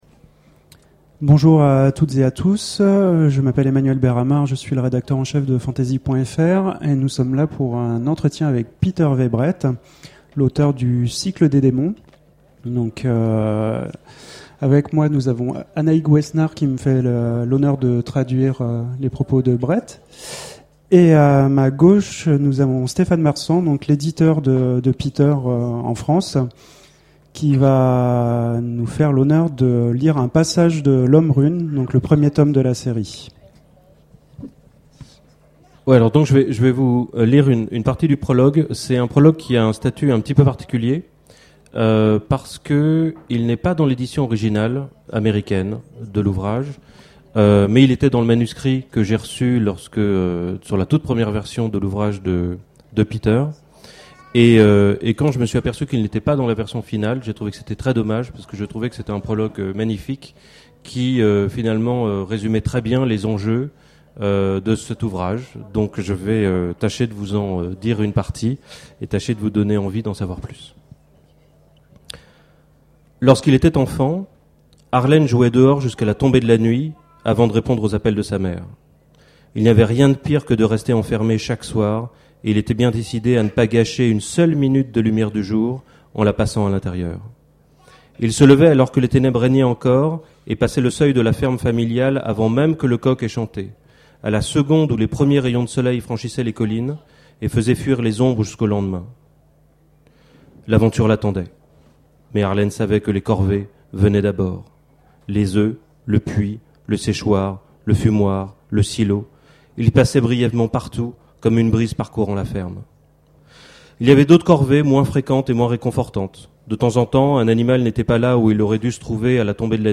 Imaginales 2011 : Conférence rencontre avec N.K. Jemisin
Parmi elles, voici la conférence qui a été consacrée à N.K. Jemisin lors de sa venue en France aux Imaginales en 2011.